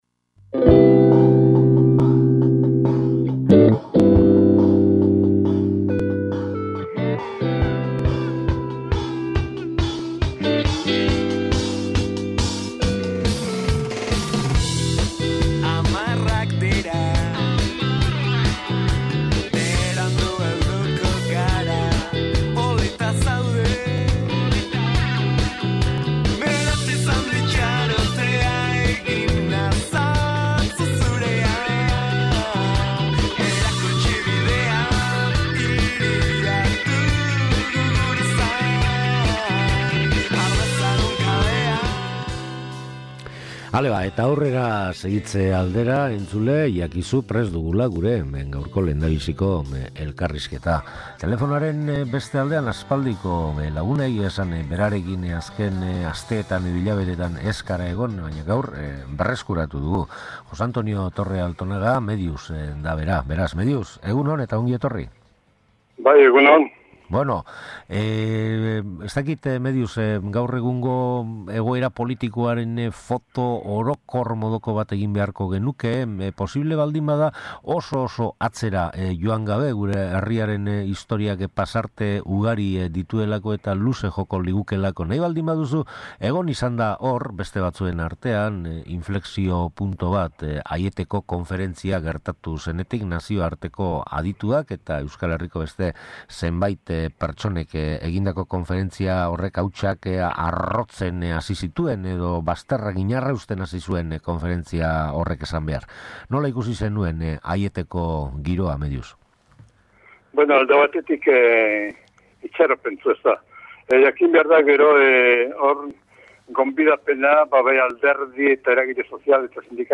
SOLASALDIA: Egoera politikoaz
SOLASALDIA